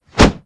zm_swing_2.wav